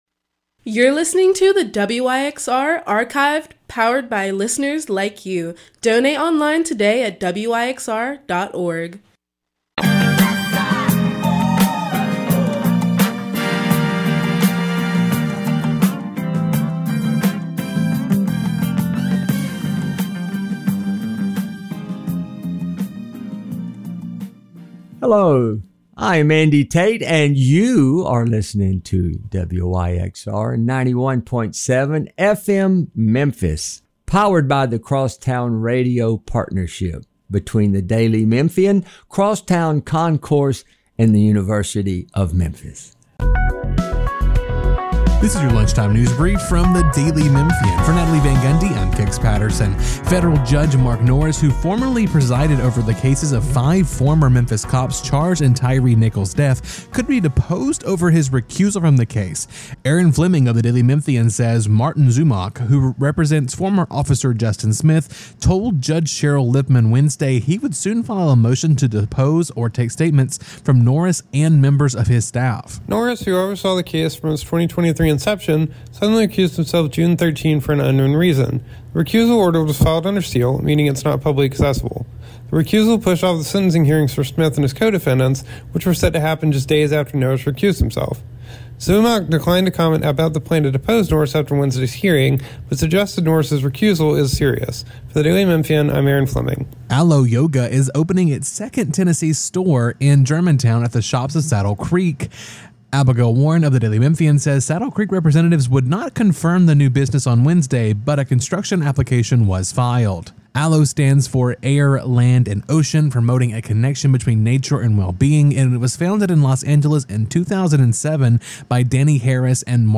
hip-hop, soul, post-disco, new jack swing
Hip Hop Funk